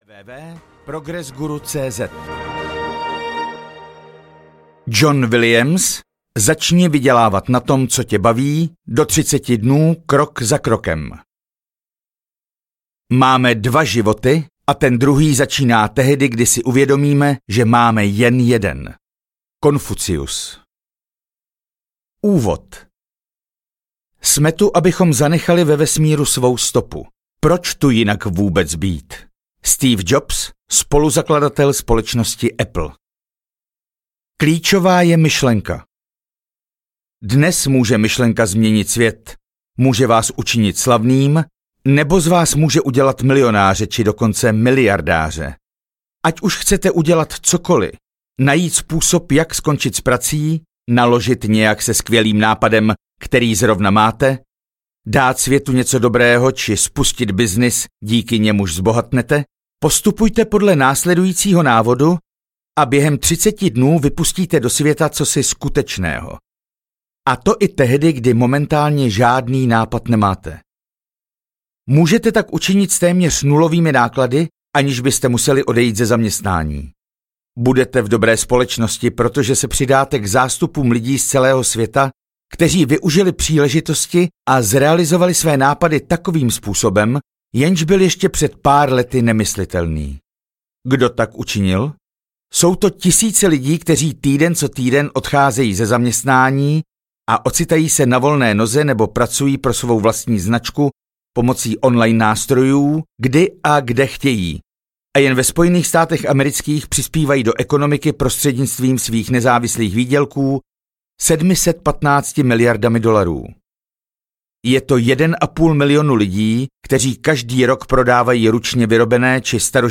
Začni vydělávat na tom, co tě baví audiokniha
Ukázka z knihy